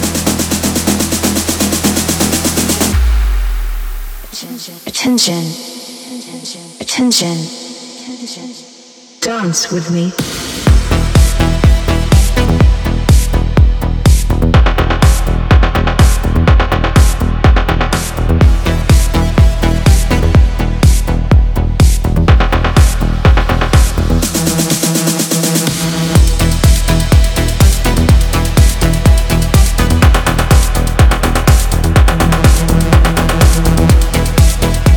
Жанр: Танцевальные / Техно